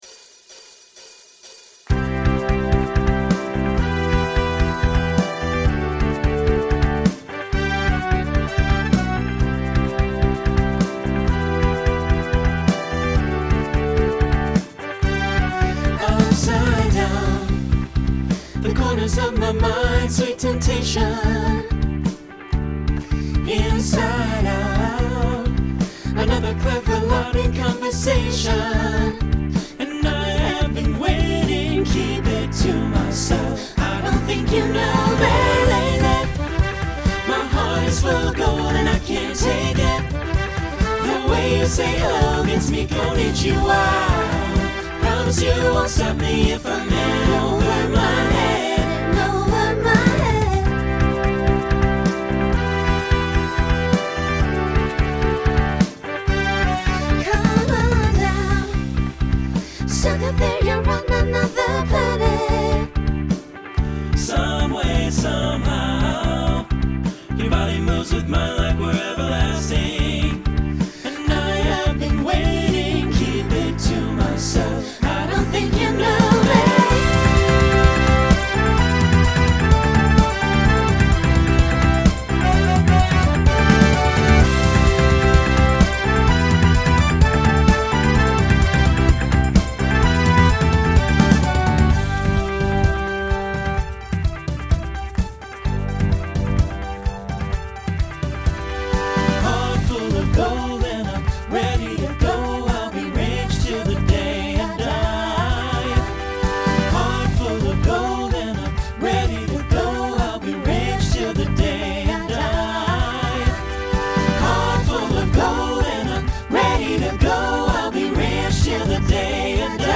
Genre Rock Instrumental combo
Mid-tempo Voicing SATB